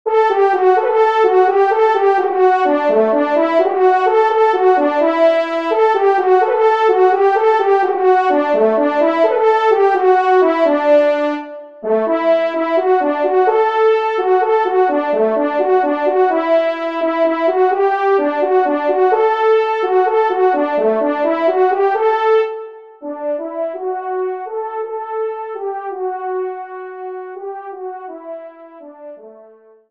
Fanfare de personnalité